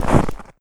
STEPS Snow, Run 30-dithered.wav